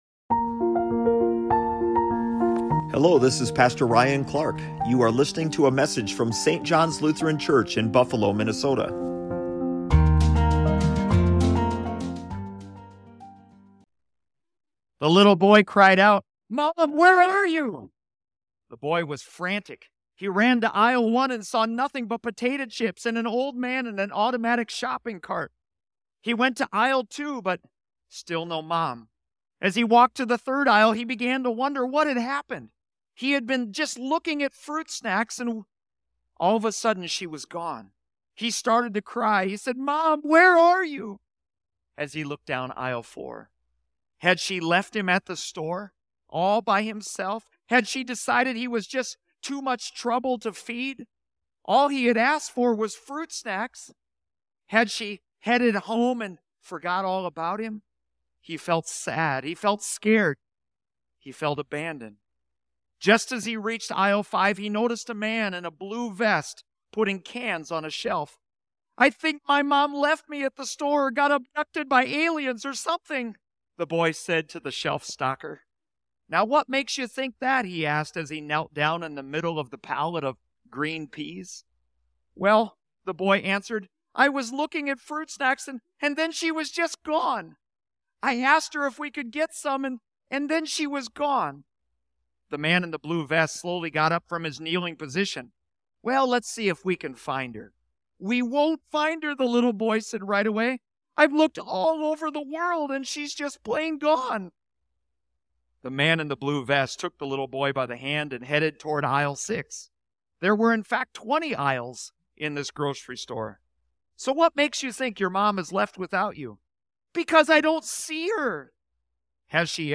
Sermon Home Doubt. Doubt is part of the human condition. But the Lord offers us hope amidst our doubts and help to overcome them.